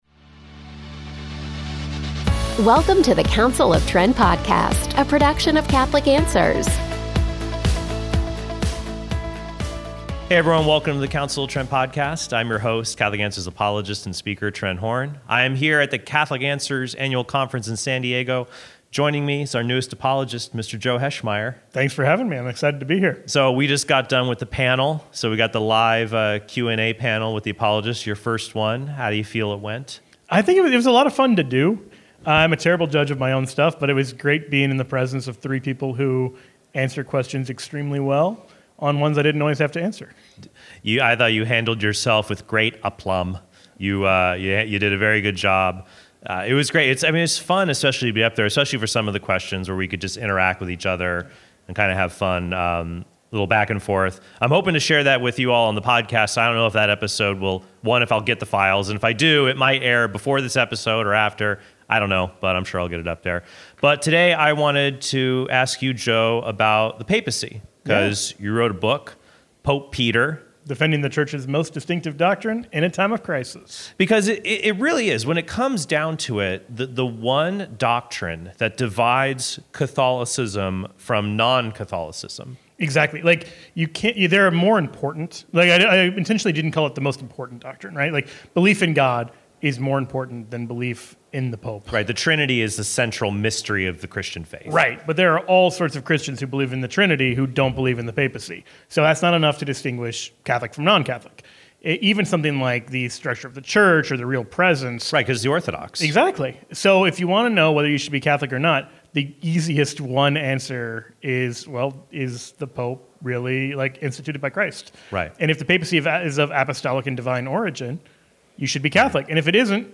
I am here at the Catholic Answers annual conference in San Diego.